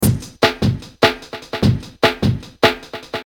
150-bpm-raw-acoustic-breakbeat-loop-punchy-kick-crisp-snare.mp3